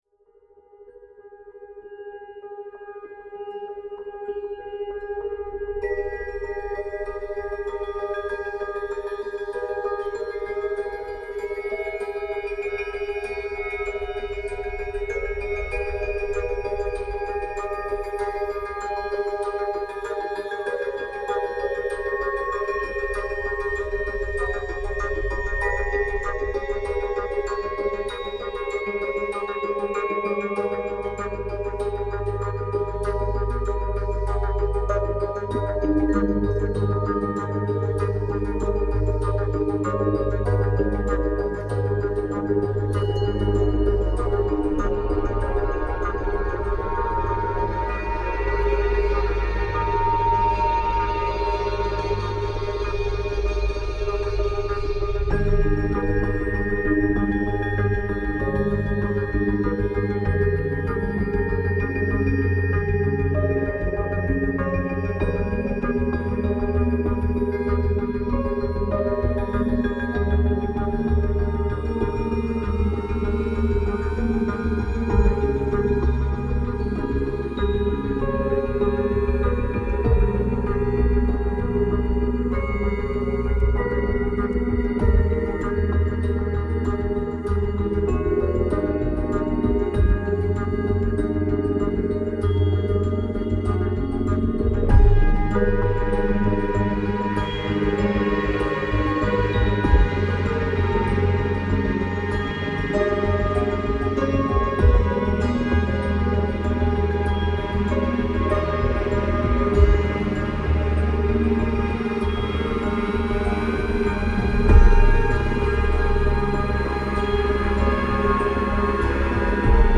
Domeniul de frecvență: BETA înalte